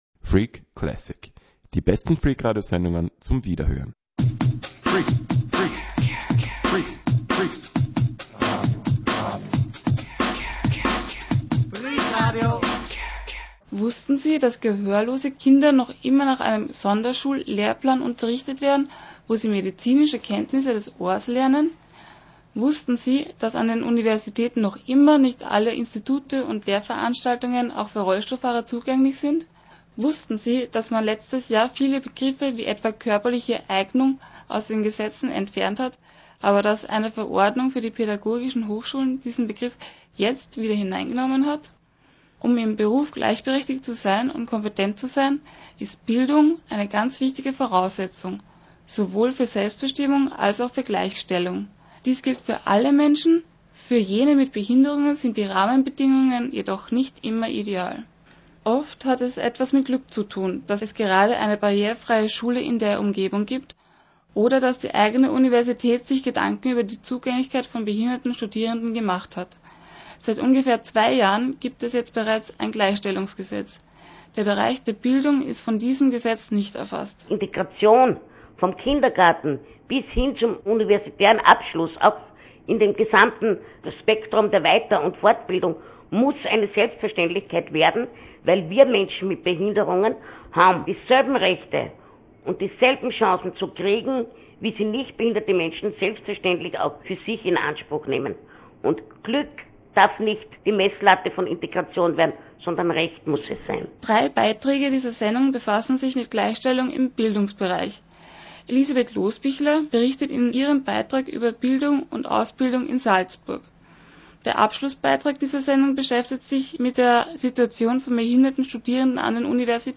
Freak Online - die Webplattform von Freak Radio, dem Radio über den barrierefreien Lifestyle.